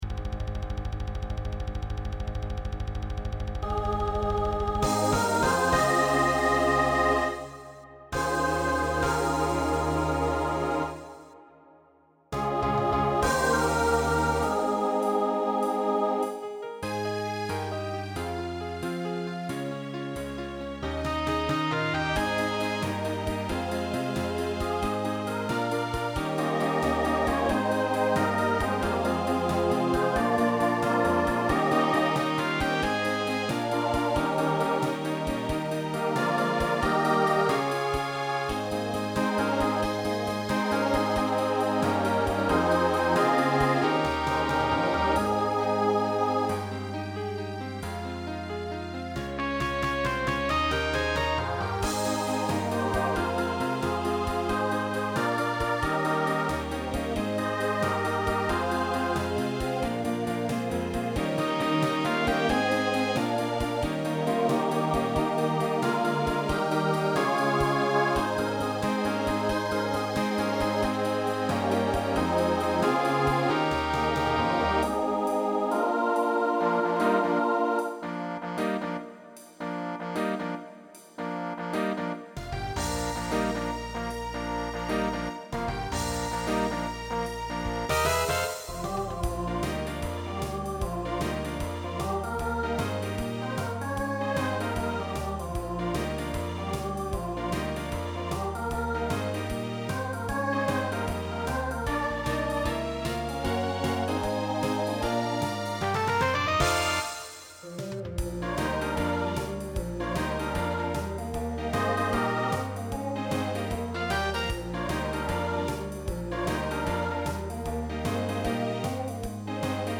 Voicing SATB Instrumental combo Genre Rock , Swing/Jazz
Mid-tempo